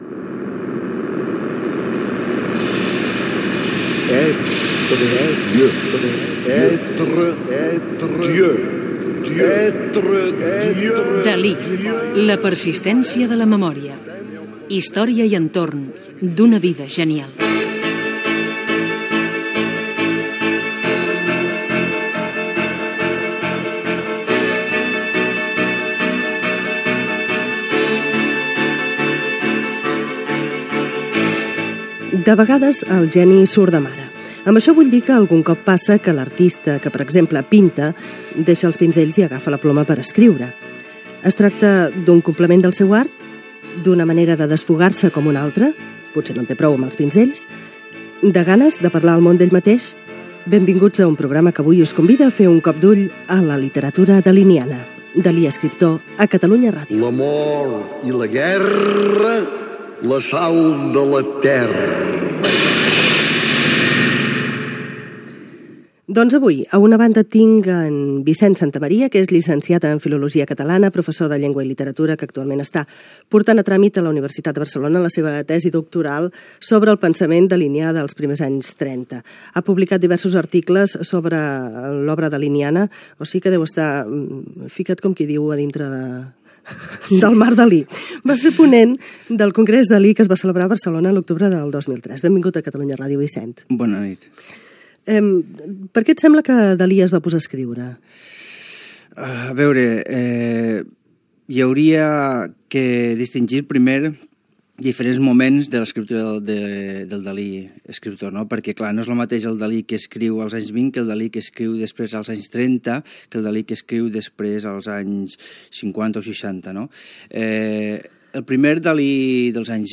Careta del programa, introducció, espai dedicat al Dalí escriptor
Cultura